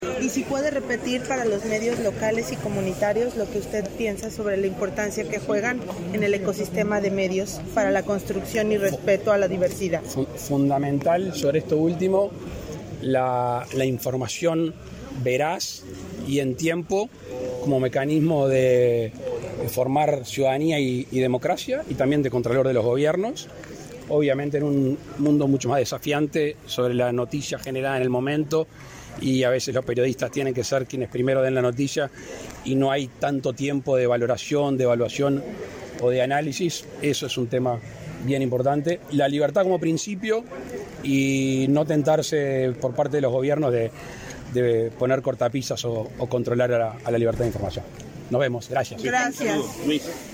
Declaraciones del presidente de la República, Luis Lacalle Pou, a la prensa
Declaraciones del presidente de la República, Luis Lacalle Pou, a la prensa 02/05/2022 Compartir Facebook X Copiar enlace WhatsApp LinkedIn El presidente de la República, Luis Lacalle Pou, fue uno de los oradores de la apertura de la conferencia de Unesco por el Día de la Libertad de Prensa, en Punta del Este, este 2 de mayo. Tras el eventó, efectuó declaraciones a la prensa.